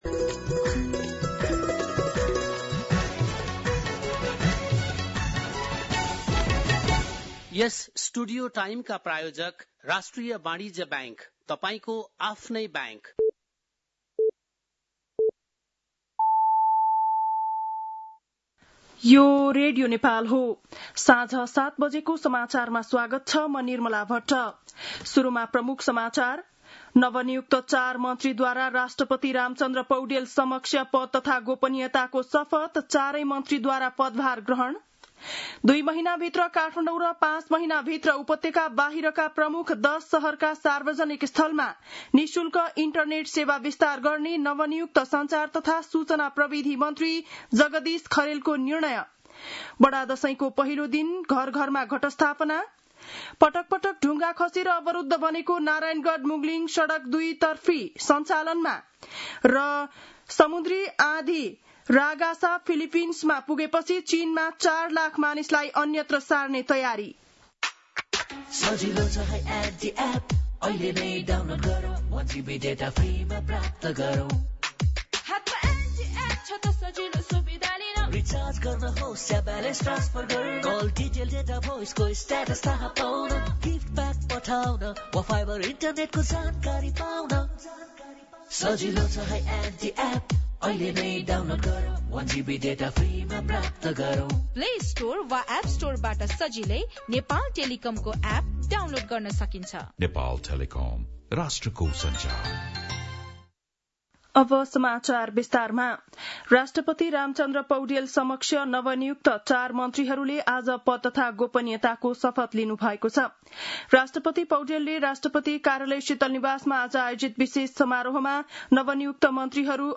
बेलुकी ७ बजेको नेपाली समाचार : ६ असोज , २०८२
7-pm-nepali-news-6-06.mp3